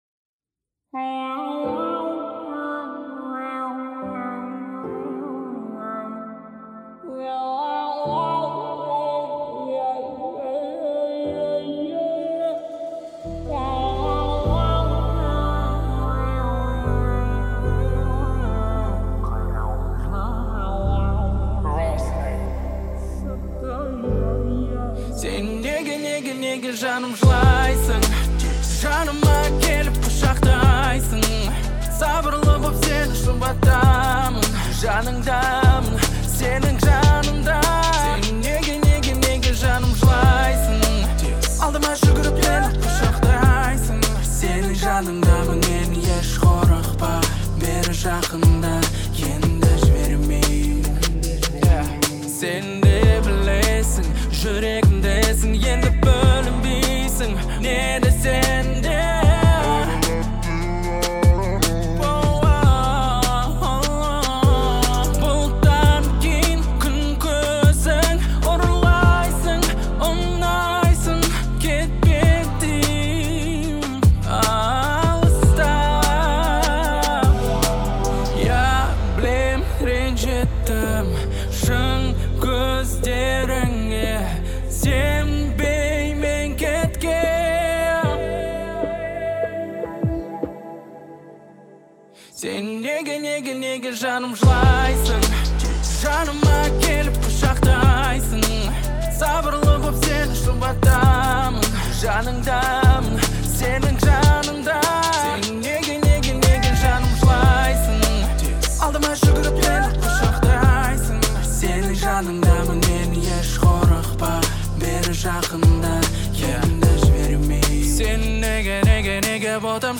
насыщенными битами и мелодичными припевами